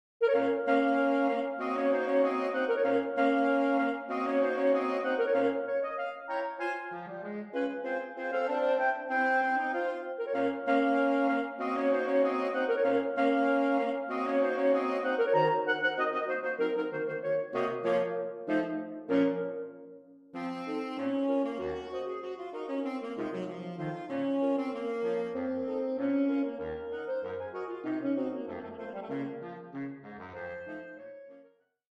Ensemble: SATB or SAAB Sax Quartet